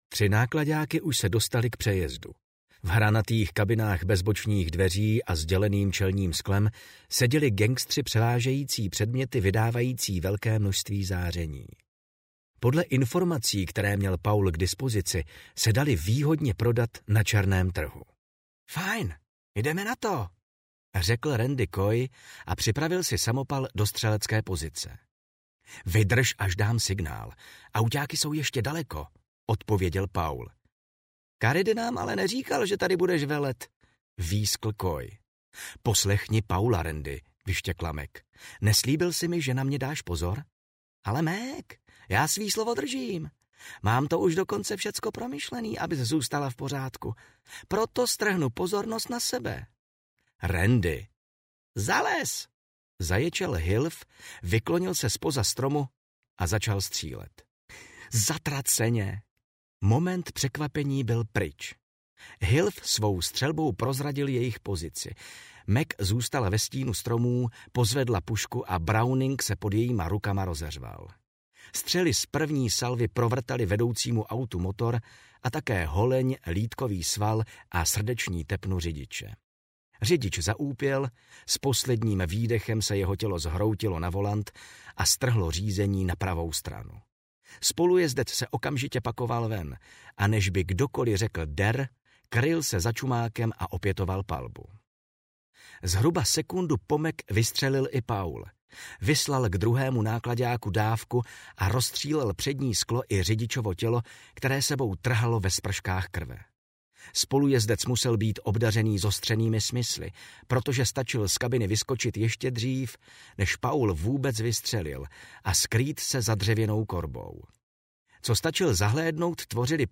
Audio knihaPřevzetí
Ukázka z knihy